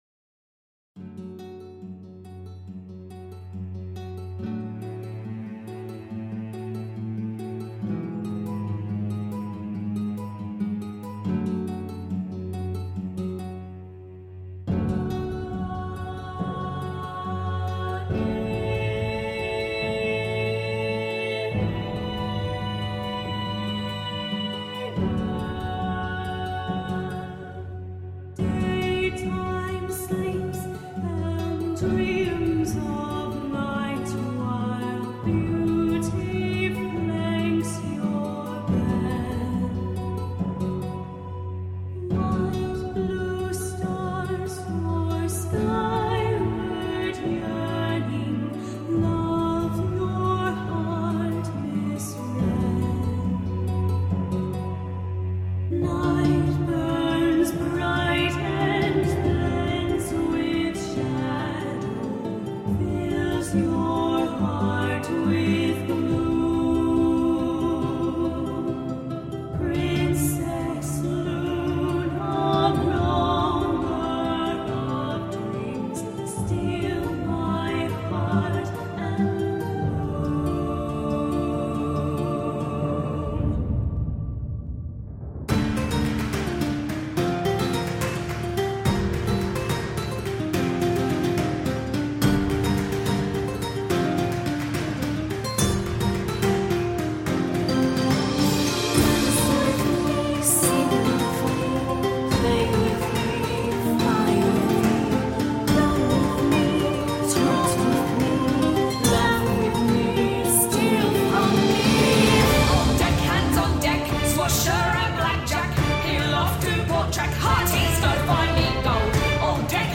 Genre: Musical/Orchestral